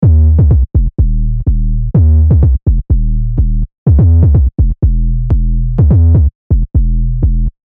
Thundering basslines, groovy rhythms, and captivating bass shots ready to elevate your sound!
BASS_-_Tech_House_2.mp3